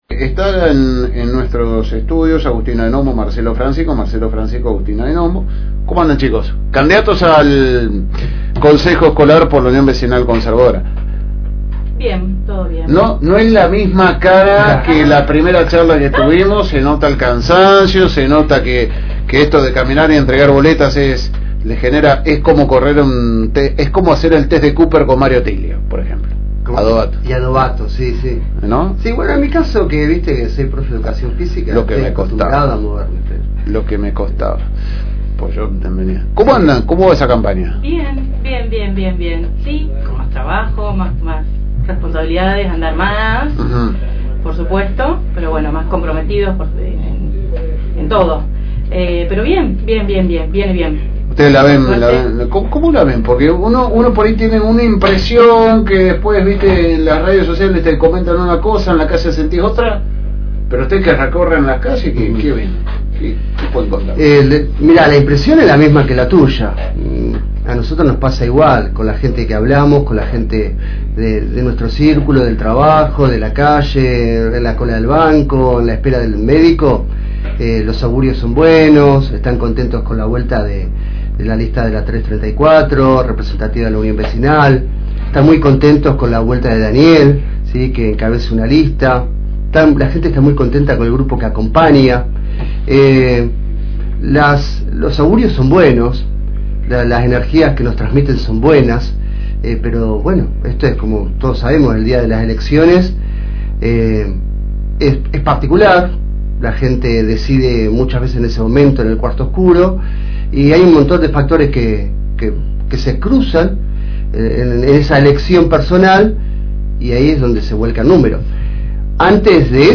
Pasaron por los estudios de la FM Reencuentro 102.9